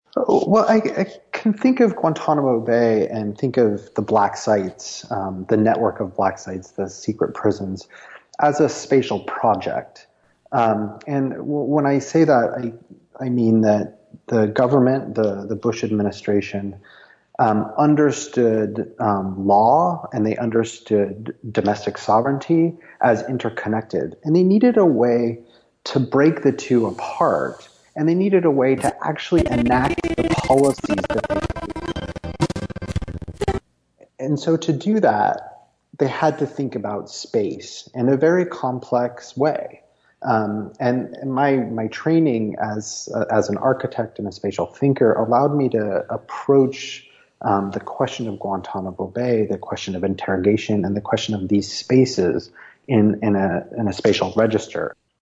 Our Skype connection for this interview was not great, and we apologize for the uneven audio in this recording.